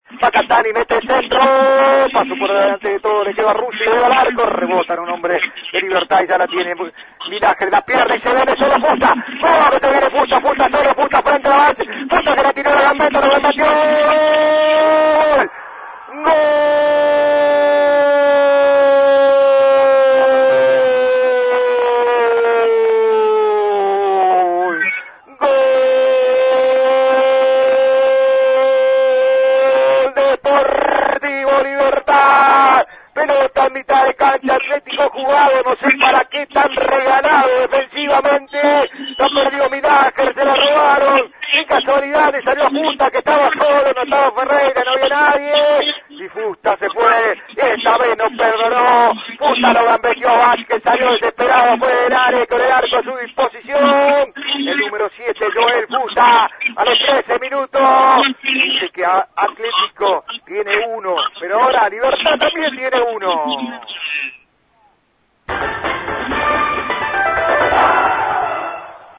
GOLES: